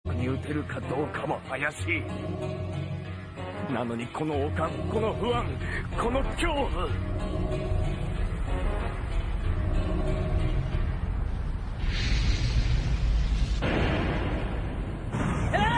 PLAY kenshin yell longer
kenshin-yell-longer-1.mp3